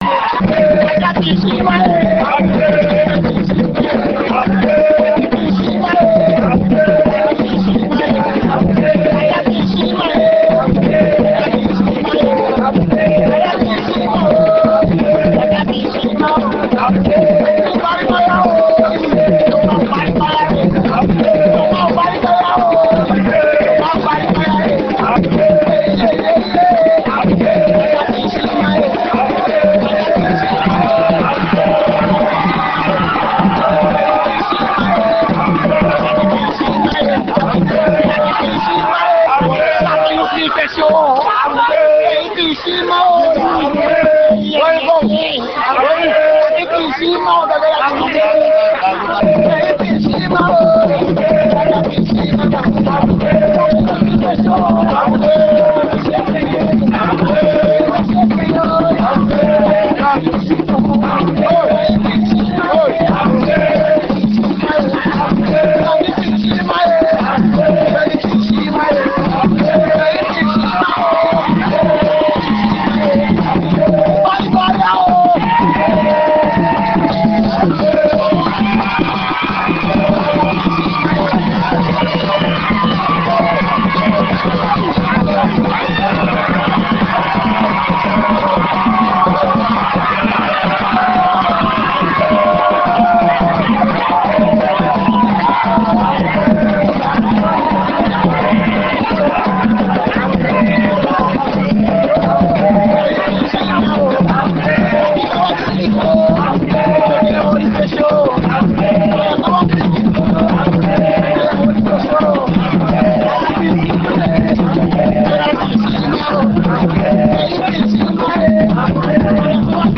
enregistrement durant une levée de deuil (Puubaaka)
danse : awassa (aluku)
Genre songe
Pièce musicale inédite